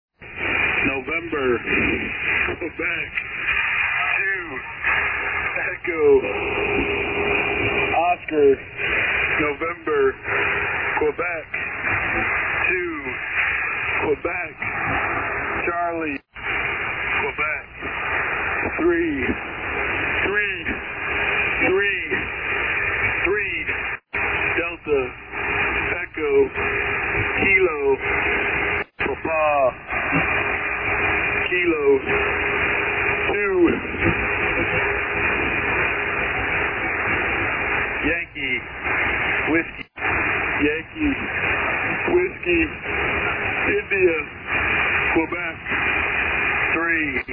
Смех